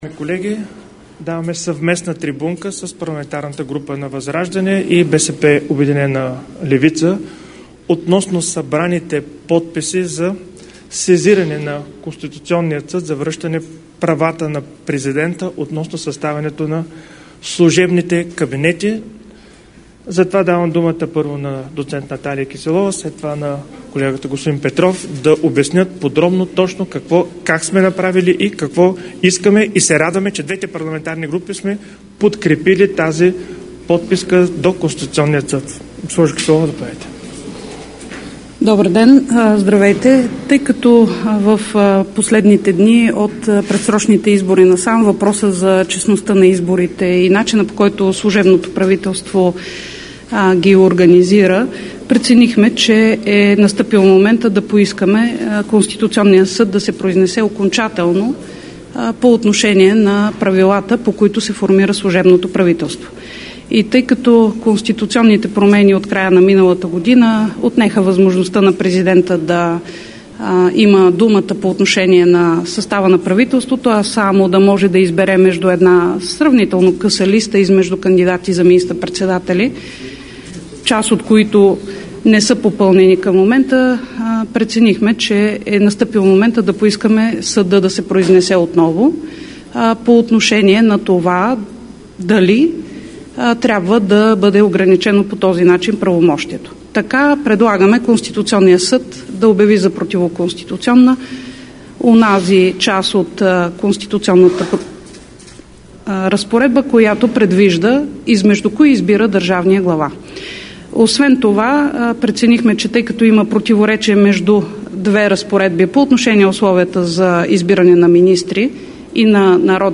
10.30 - Брифинг на БСП и Възраждане за сезиране на КС за връщане на служебните кабинети на президента. - директно от мястото на събитието (Народното събрание)